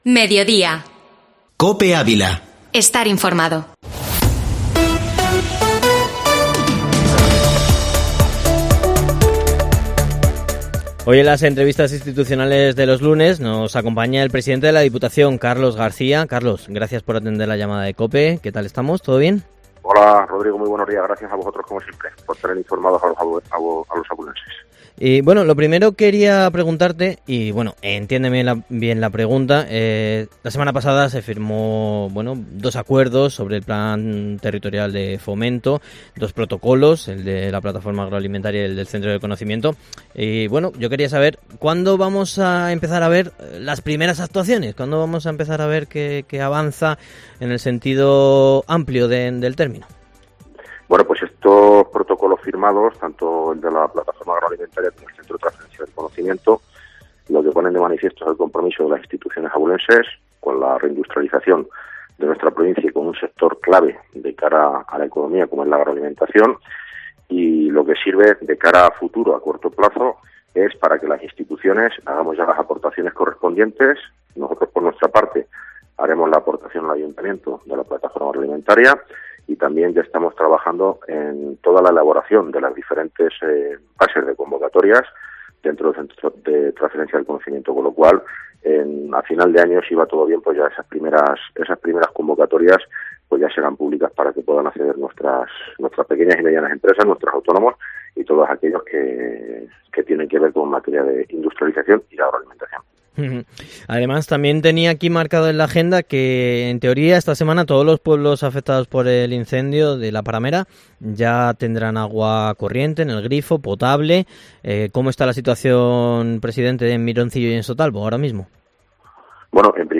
La entrevista completa